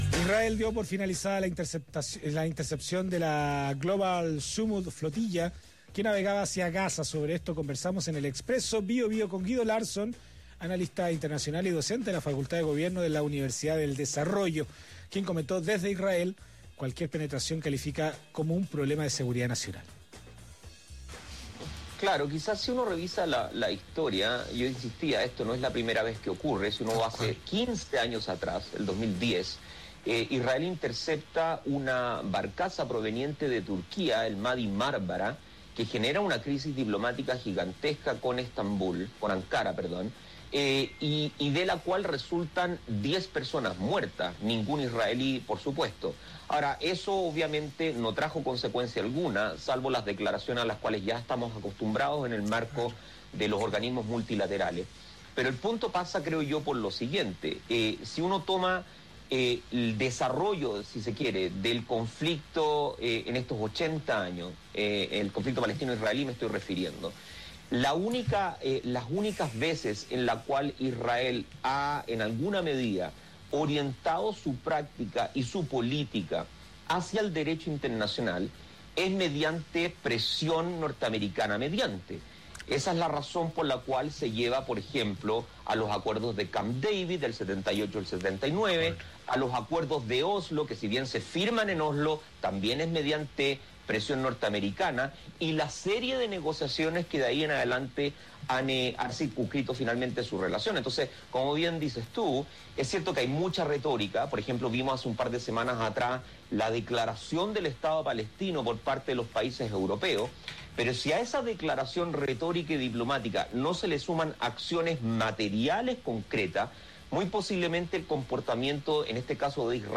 en entrevista con El Expreso Bío Bío